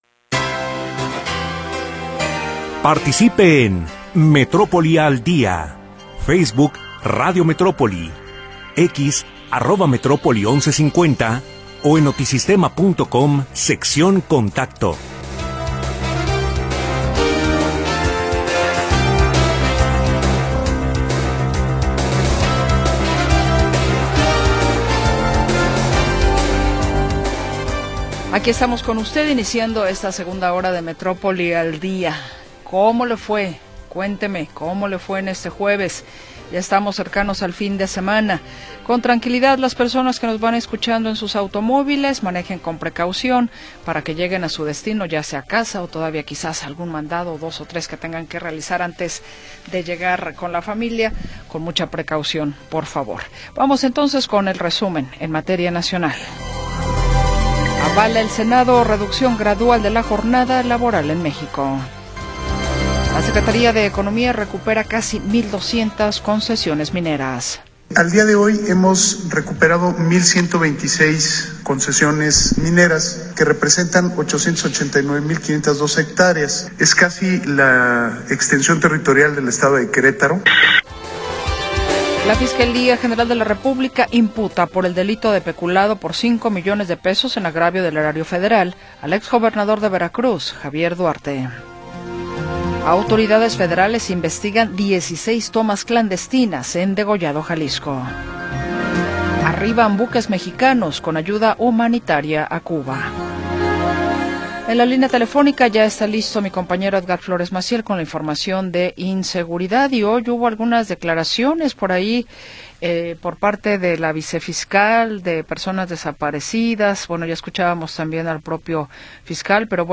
Segunda hora del programa transmitido el 12 de Febrero de 2026.